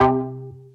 Synth Stab 22 (C).wav